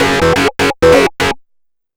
RIFFSYNT01-R.wav